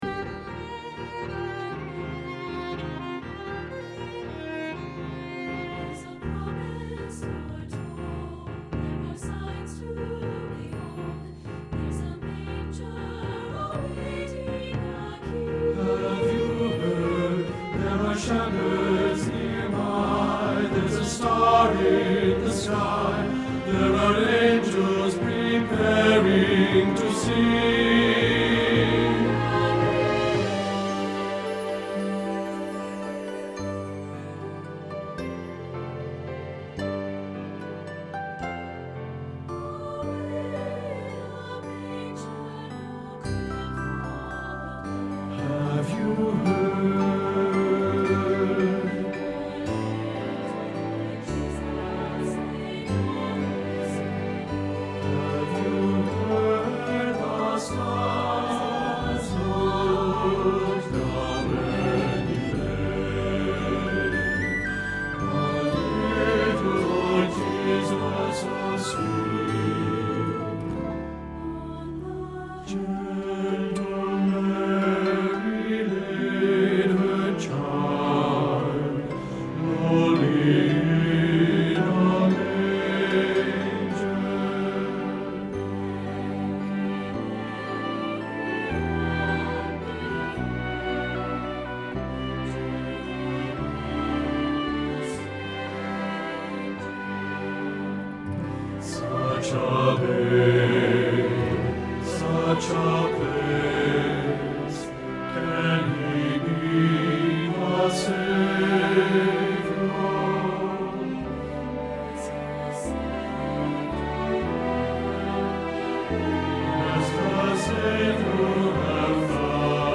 Have You Heard – Bass – Hilltop Choir